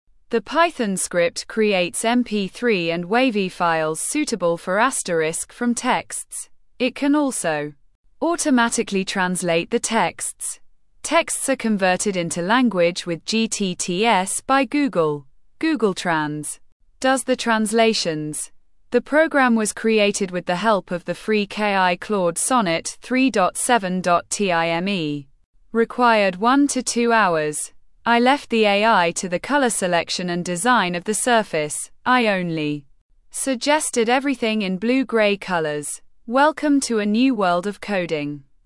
Unterstützt werden Deutsch, Englisch, Schwedisch, Französisch und Spanisch, wobei die englische Stimme derzeit nur mit britischem Akzent verfügbar ist.
Klangbeispiel eines deutschen Textes als MP3: Die Stimme ist bei Google gTTS immer weiblich.